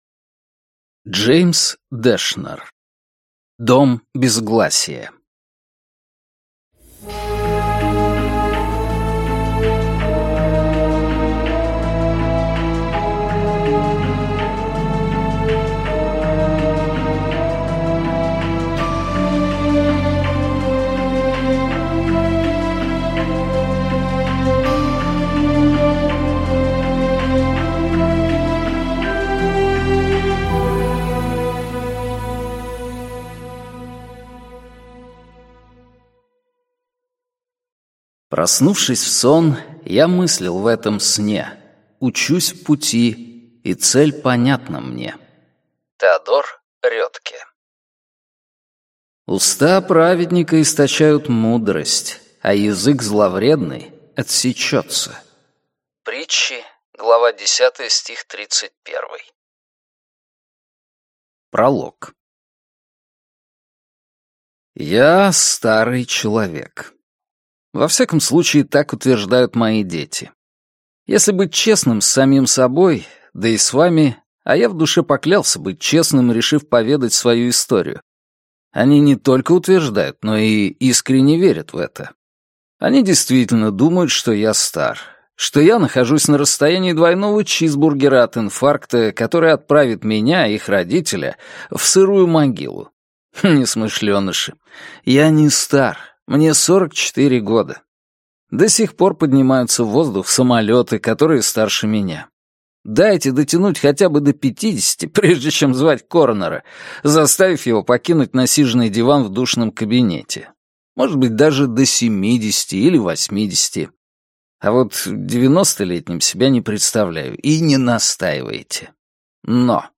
Аудиокнига Дом Безгласия | Библиотека аудиокниг